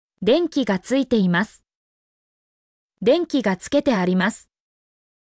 mp3-output-ttsfreedotcom-68_0btG6MDw.mp3